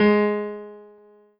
piano-ff-36.wav